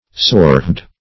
Sorehead \Sore"head`\, n.